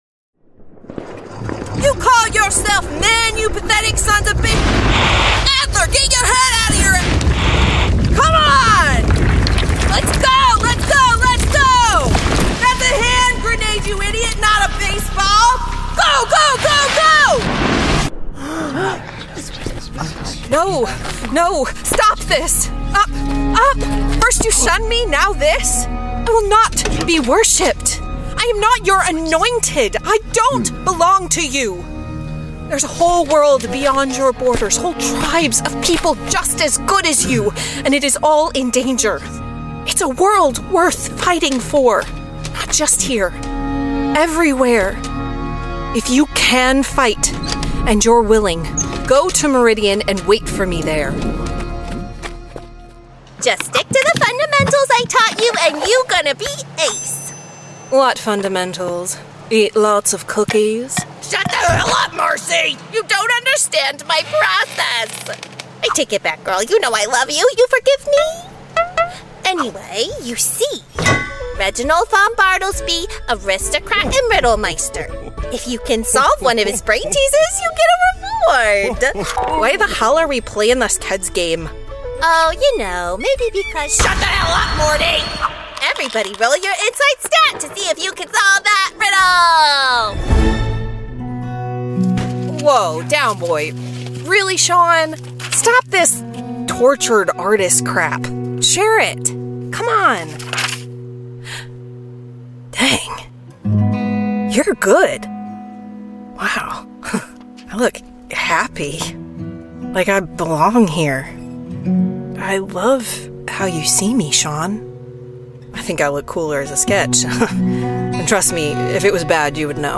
Playing age: Teens - 20s, 20 - 30sNative Accent: North AmericanOther Accents: American, Australian, Irish, London, RP, Scottish
• Native Accent: American Standard, Texan